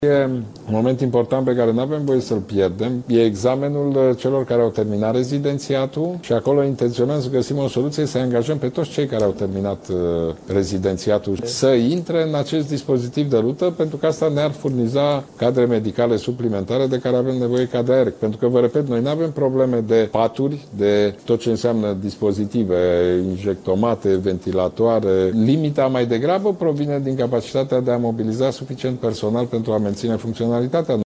19oct-12-Orban-B1-despre-rezidenti.mp3